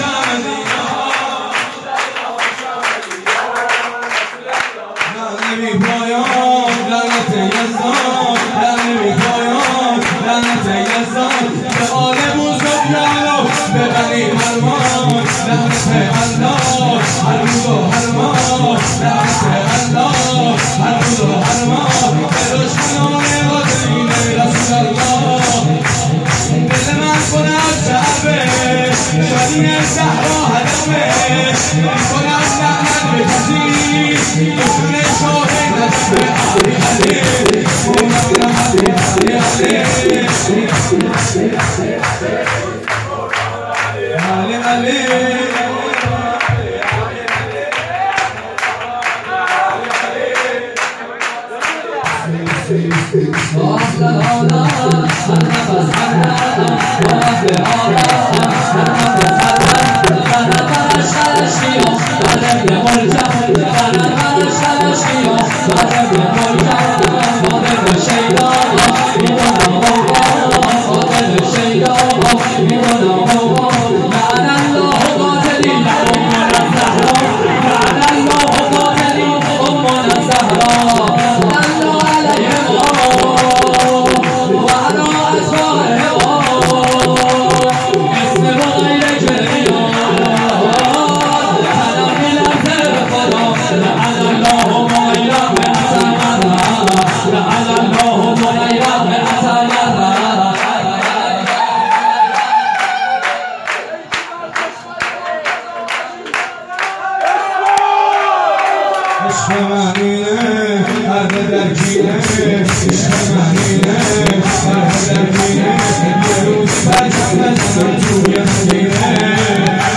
سرود
ذاکرین شهرستان گرمه